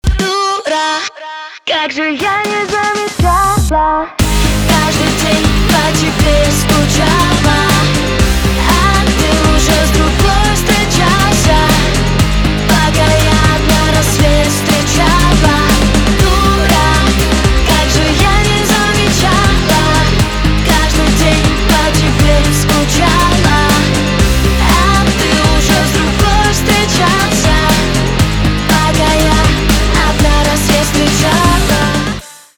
поп
гитара , барабаны
грустные , печальные